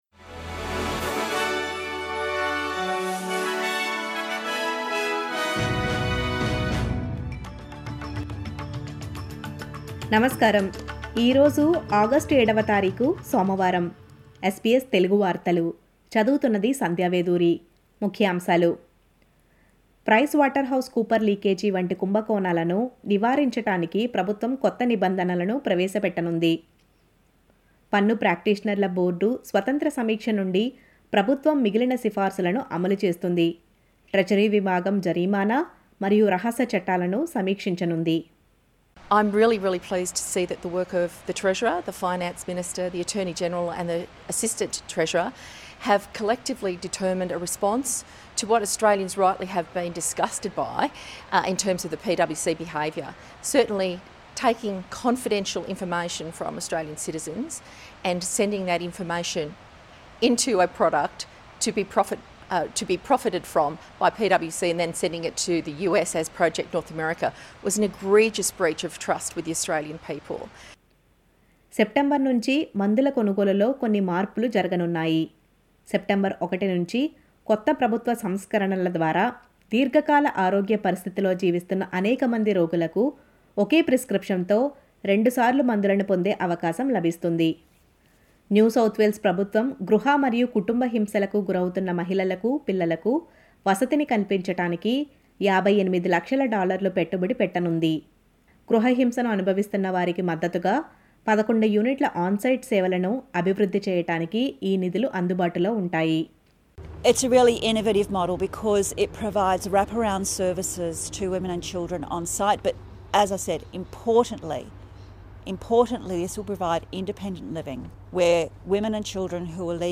SBS తెలుగు 07/08/23 వార్తలు: సెప్టెంబర్ నుంచి మందుల కొనుగోలు లో కొన్ని మార్పులు.
నమస్కారం, ఈ రోజు ఆగష్టు 7 వ తారీఖు సోమవారం.